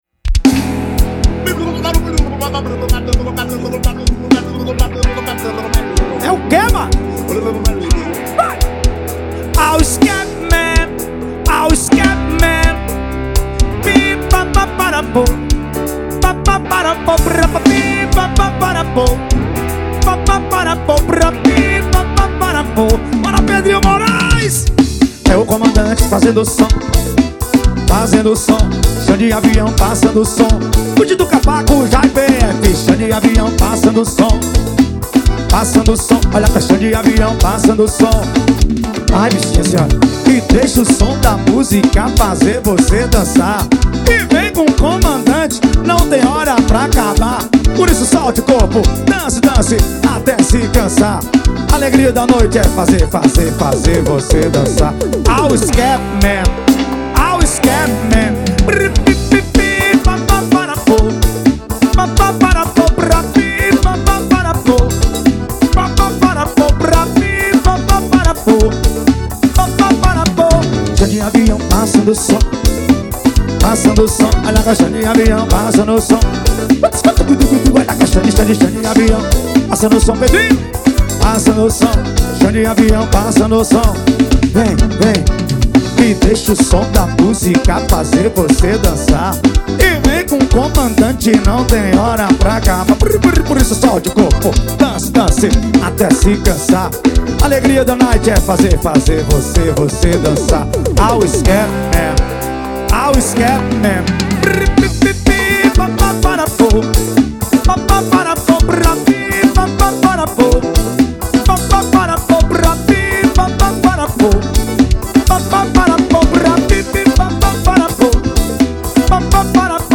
2024-02-14 18:42:51 Gênero: FORRO Views